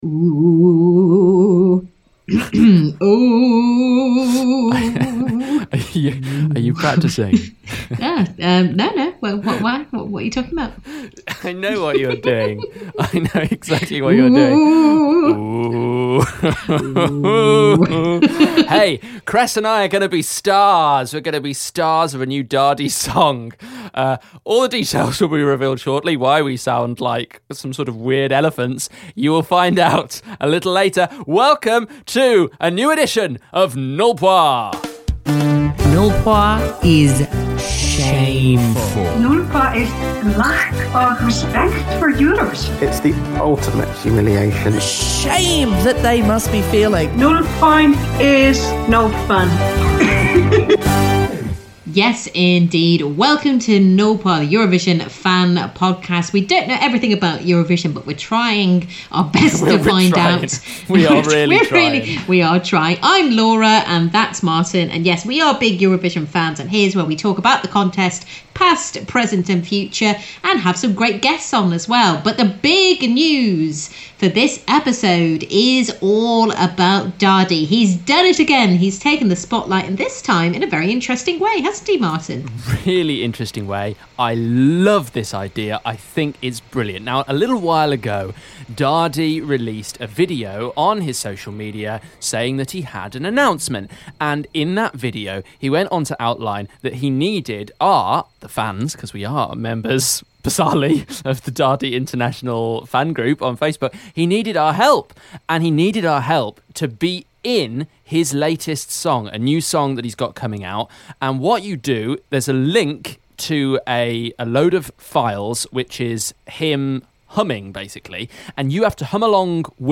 Iceland's Daði has asked for their help and they're more than willing to lend their singing voices.